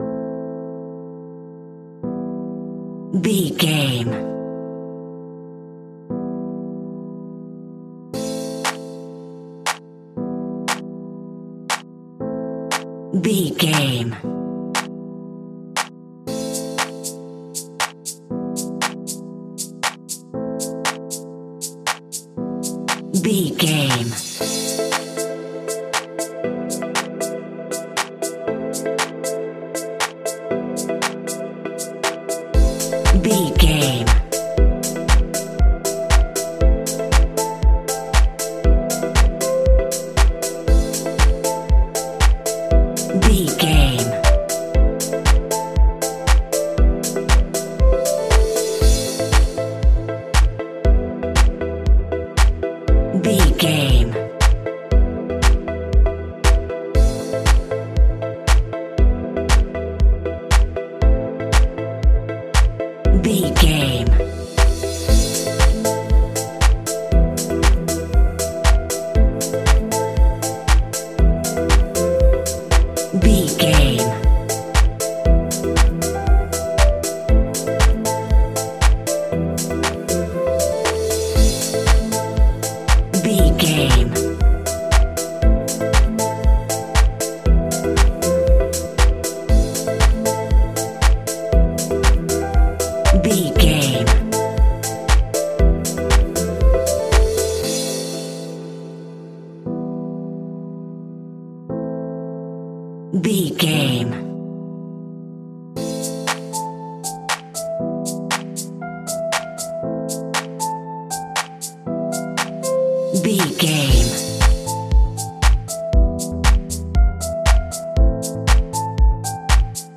Aeolian/Minor
dark
futuristic
epic
groovy
drum machine
synthesiser
electric piano
house
electro house
synth leads
synth bass